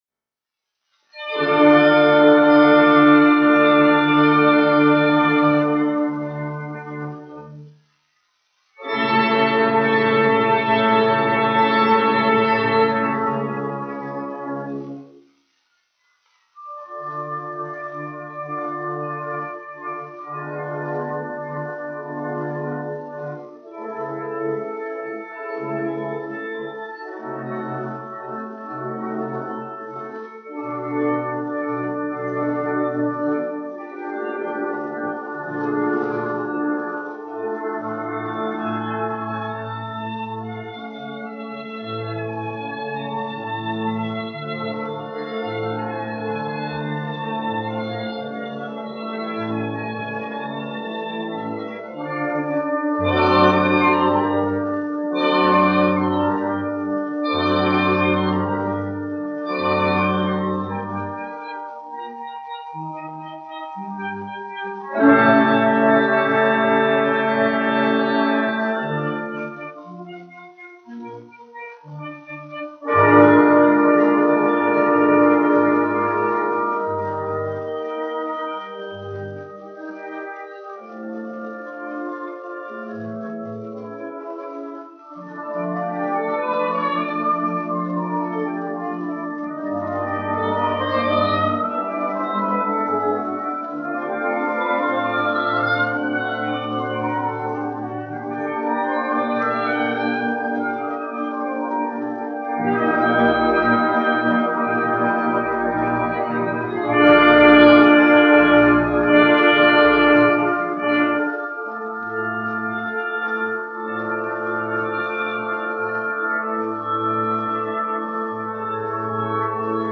Staatskapelle Berlin, izpildītājs
1 skpl. : analogs, 78 apgr/min, mono ; 25 cm
Uvertīras
Skaņuplate